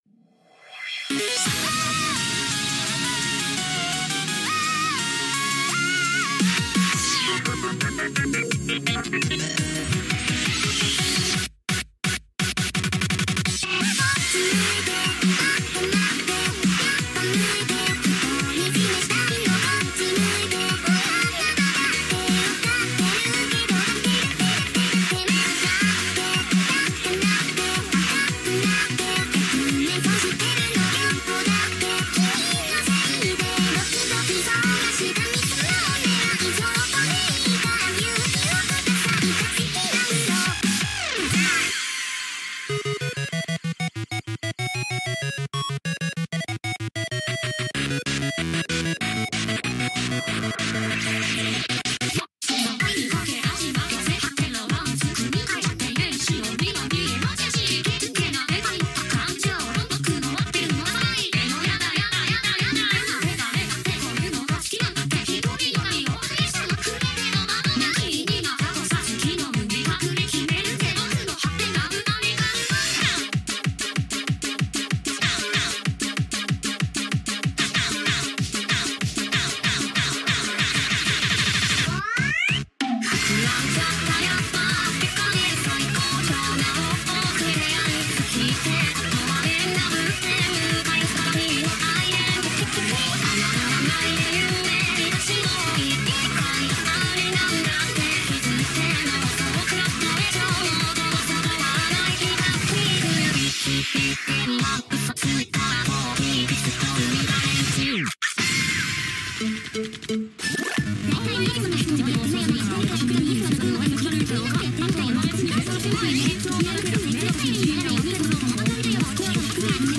Tags: vocaloid